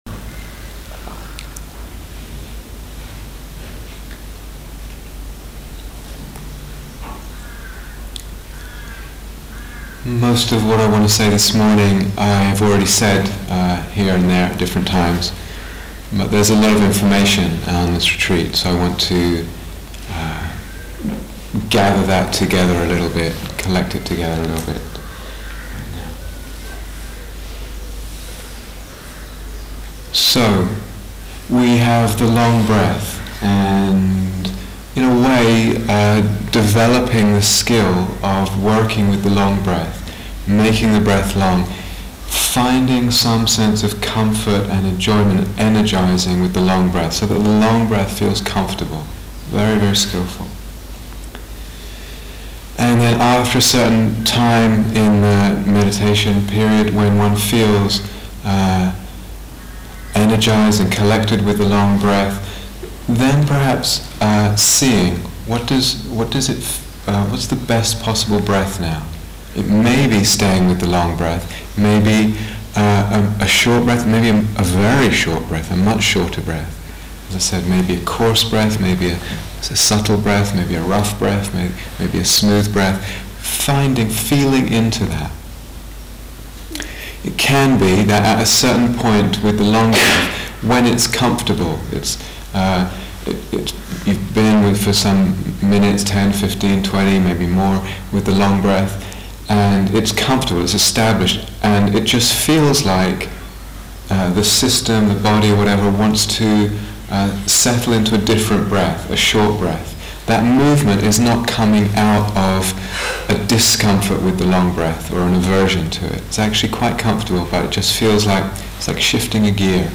Second Samatha Instructions and Guided Meditation